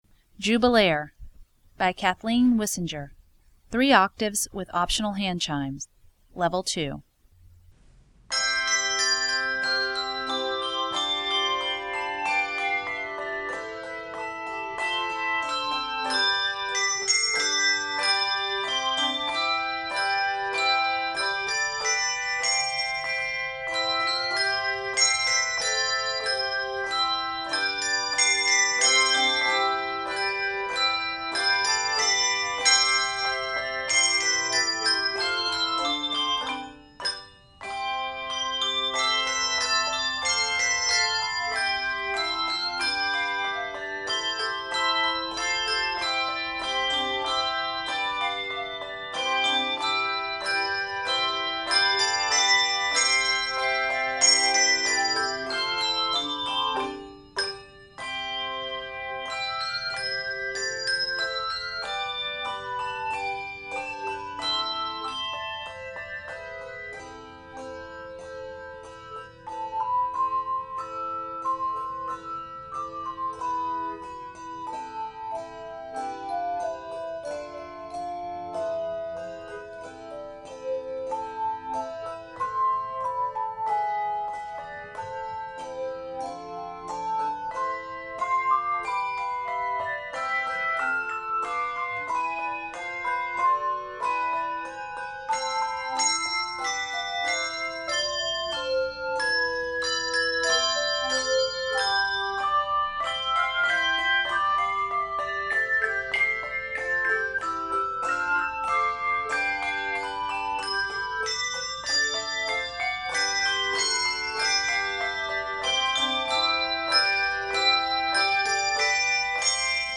Voicing: Handbells 3 Octave